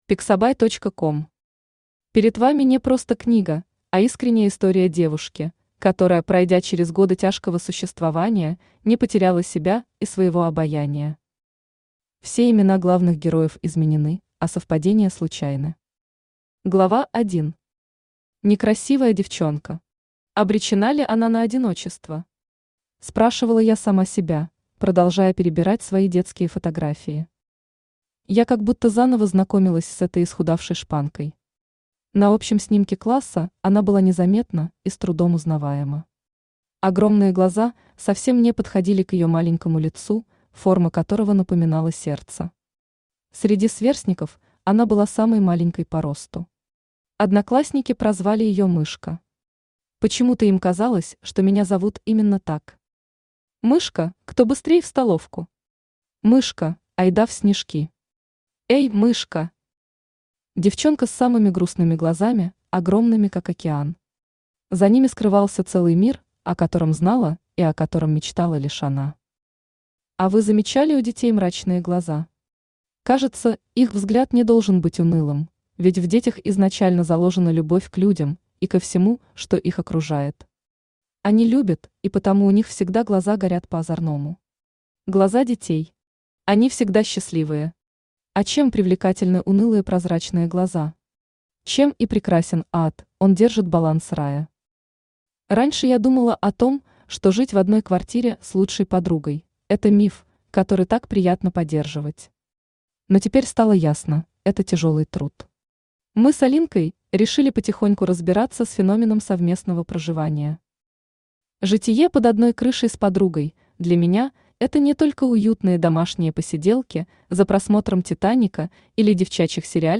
Aудиокнига Девушка в чужом платье Автор Анна Полтарабатько Читает аудиокнигу Авточтец ЛитРес.